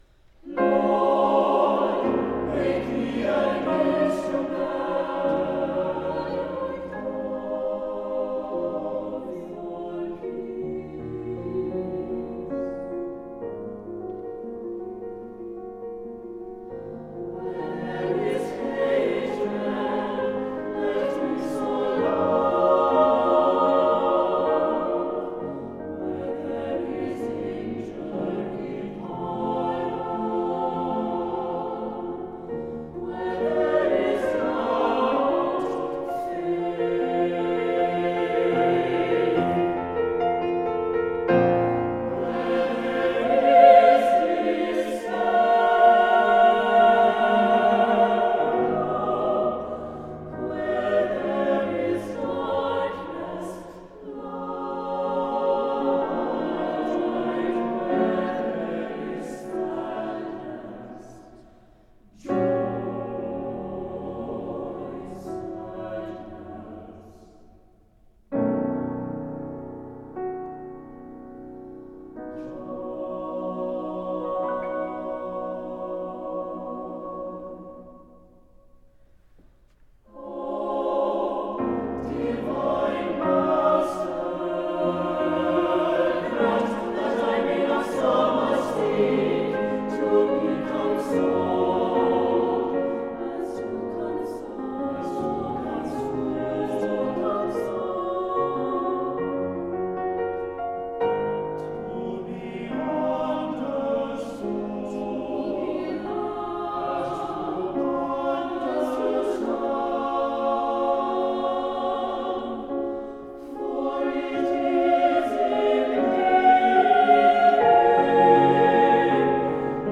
for SATB chorus and piano